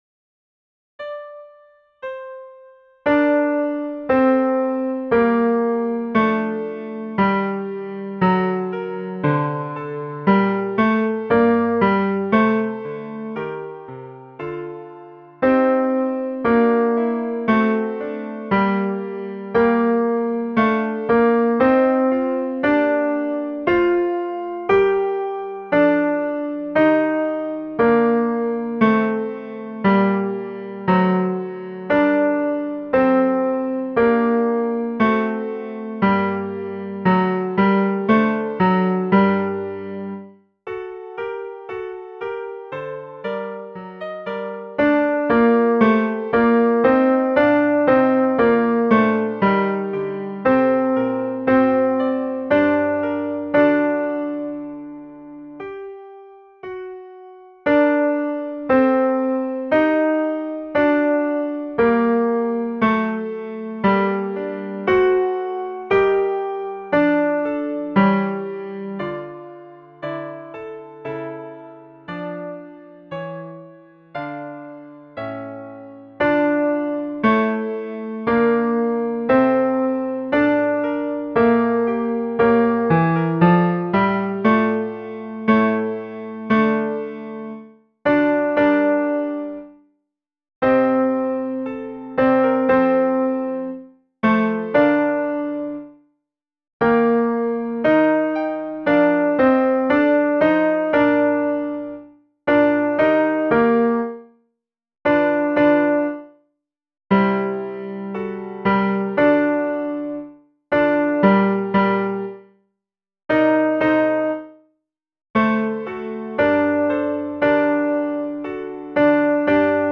CHORUS
Ténors
with_drooping_wings_tenors.mp3